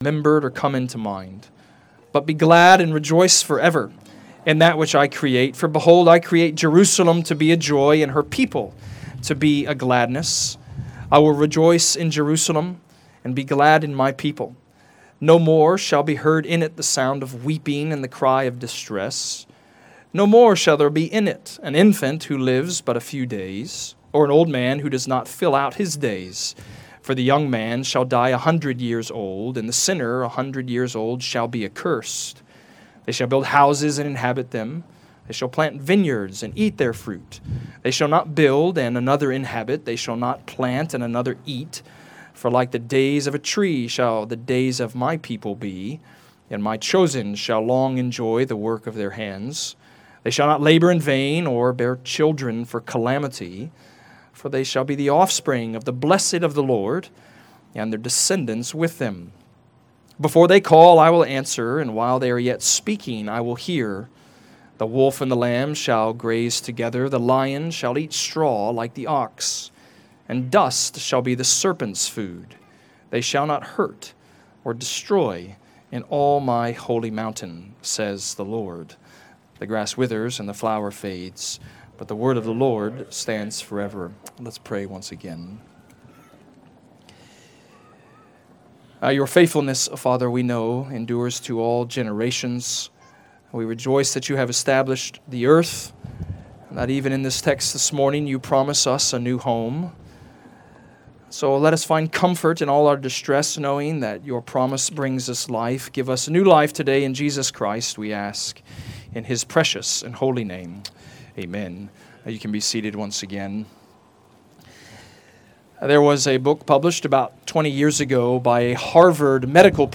Hope For The Disappointed Redeemer Presbyterian Church: Sermon Audio podcast